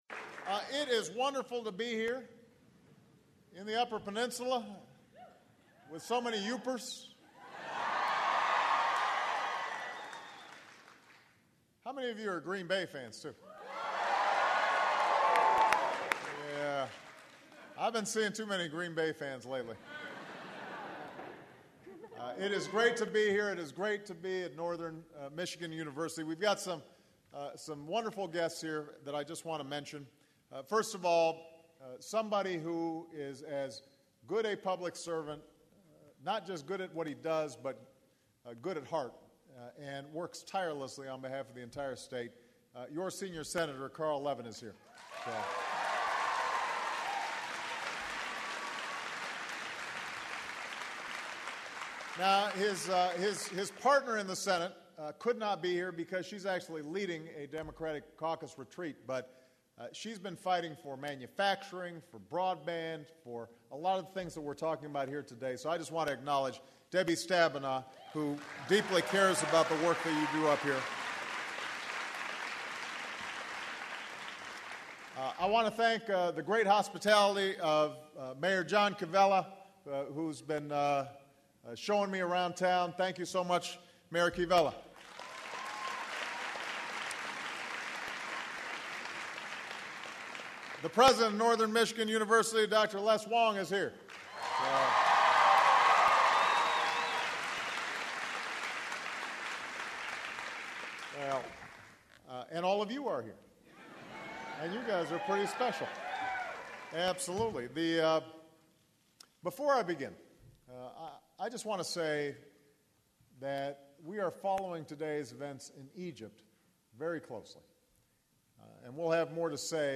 President Barack H. Obama discusses the National Wireless Initiative during a speech at Northern Michigan University in Marquette, MI. Obama talks about the investment needed in U.S. infrastructure and cites the Transcontinental Railroad, the Rural Electrical Administration, and the Interstate Highway System as successful initiatives from the past. He stresses the need to upgrade and expand access to the Internet through fiber optic and wireless networks and praises Northern Michigan University and the city of Marquette for providing broadband access in the region.
Recorded at Northern Michigan University, Feb. 10, 2011.